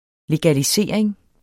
Udtale [ legaliˈseˀɐ̯eŋ ]